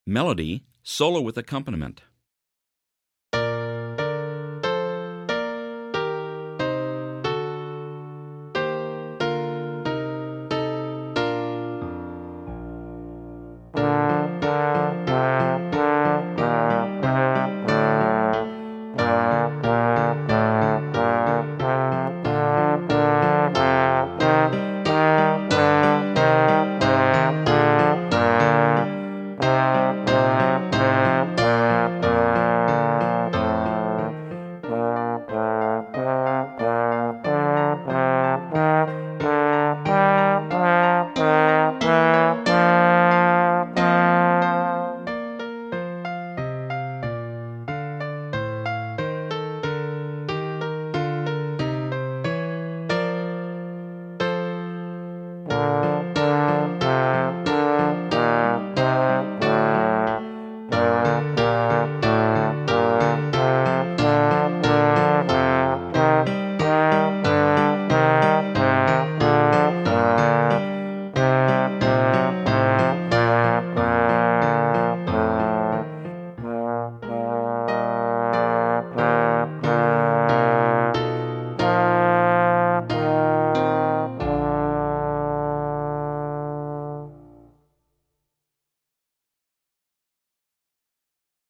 Solo with piano accompaniment
03-Melody-Solo-With-Accompaniment.mp3